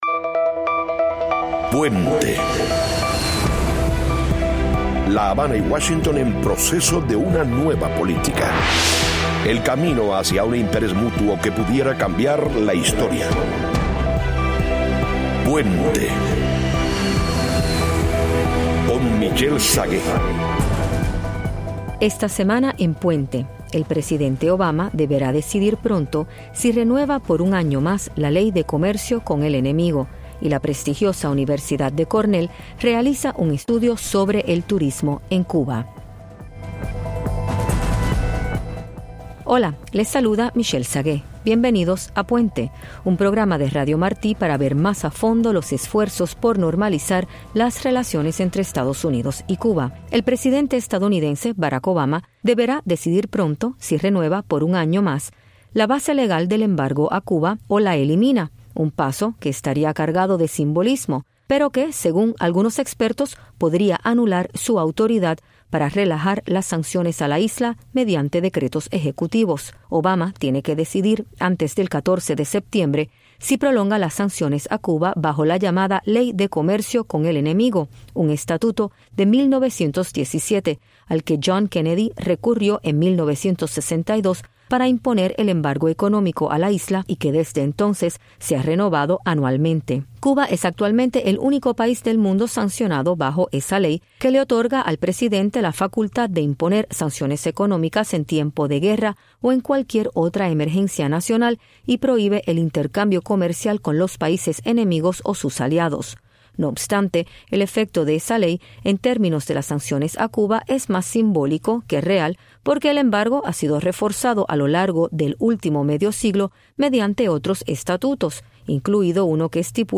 Conversamos con expertos acerca del posible levantamiento del embargo y de las contradicciones y riesgos que provocaría una decision definitiva.